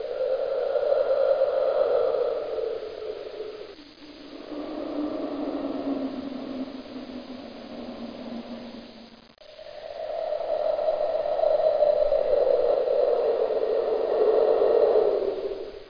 1 channel
00047_Sound_Wind.mp3